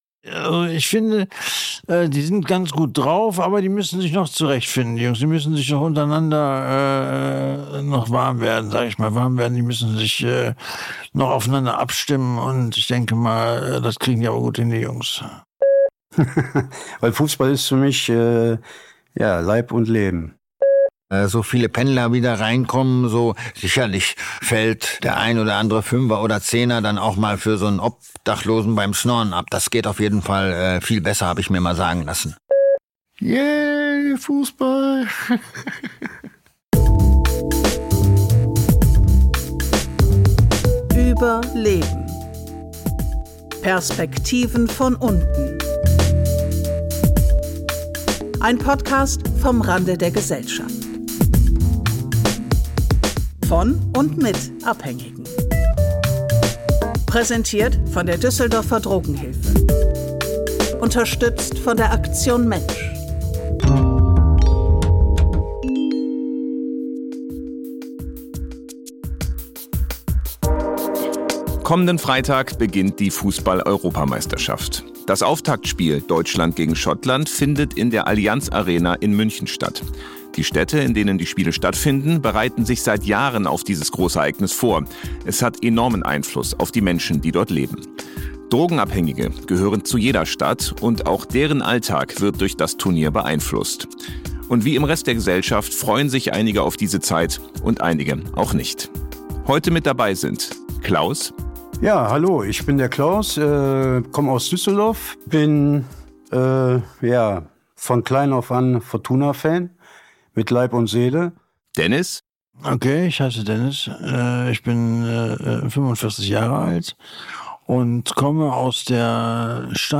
In dieser Episode sprechen Abhängige über Fußball und die anstehende Europameisterschaft und was das Turnier für den Alltag drogensüchtiger Menschen bedeutet. Am Ende wird natürlich auch getippt.